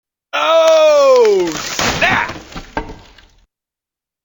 snap.mp3